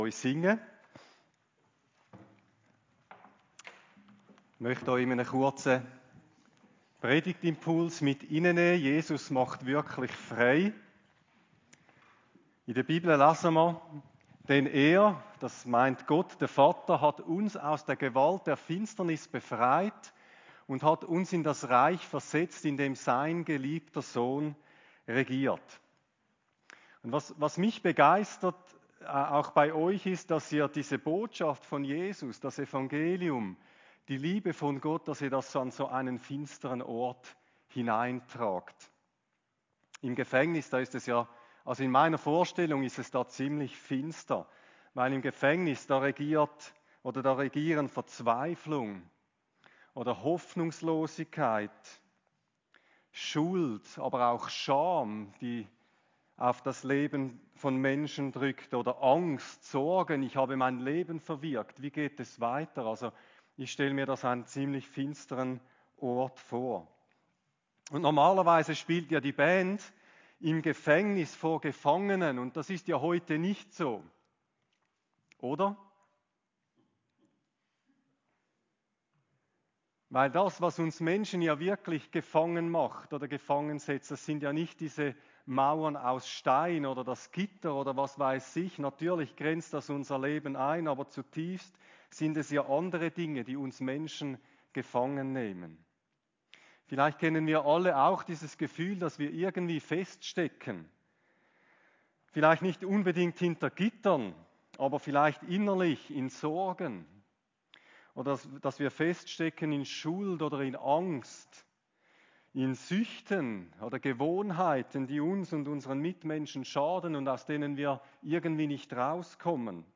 Predigt-12.4.26.mp3